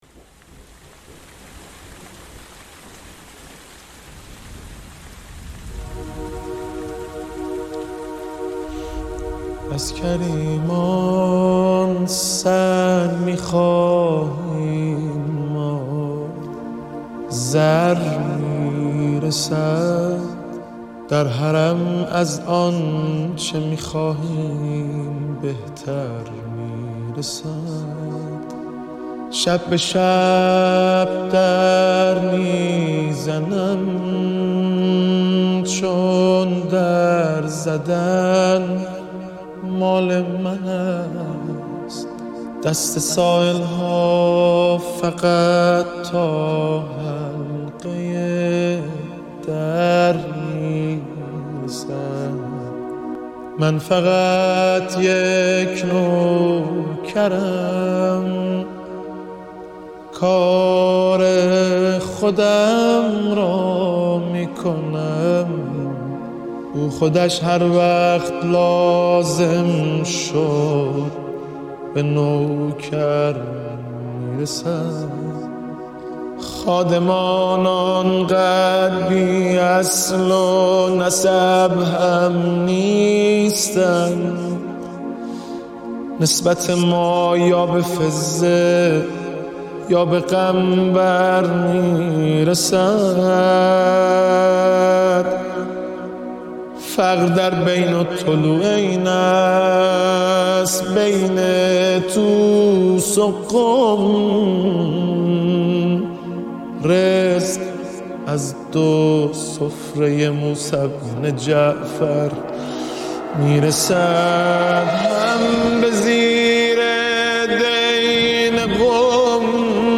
پخش آنلاین نوحه